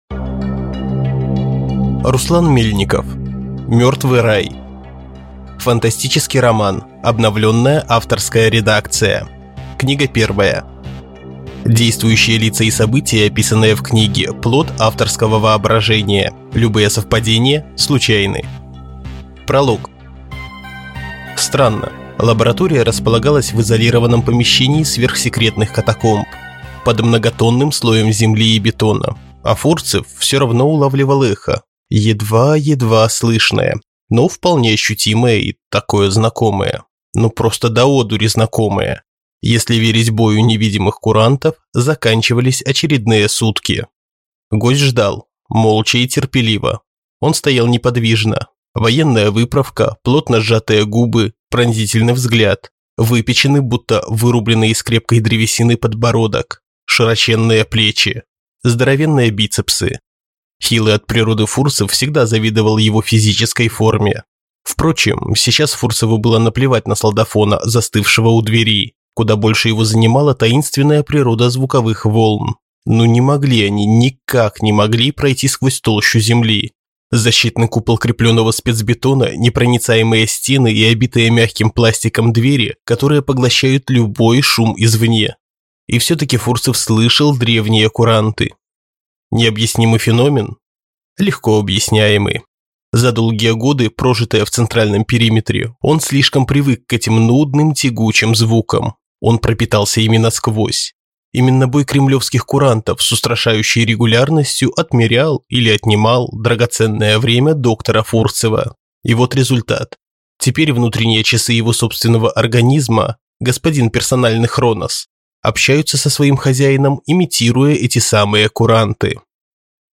Аудиокнига Мертвый рай | Библиотека аудиокниг